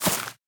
sounds / block / vine / climb1.ogg
climb1.ogg